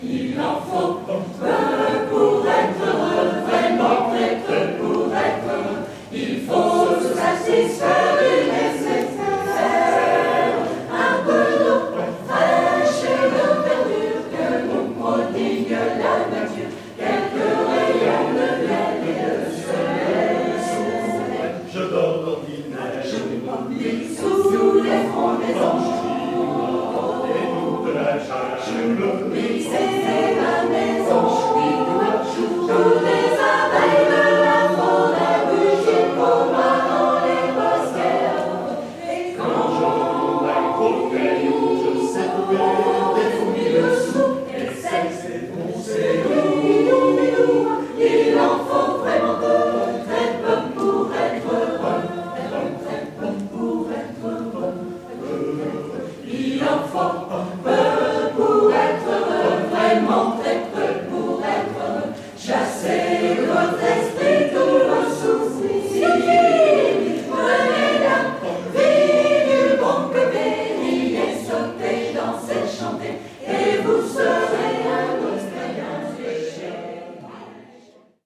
Les dernières Multiphoniades se sont déroulées du 13 au 18 mai 2014 à Lestrem.
Pour cette manifestation, “Chœur à Cœur” s’est produit le samedi 17 mai en ouvrant le concert et passant ainsi le flambeau à la chorale de Lestrem.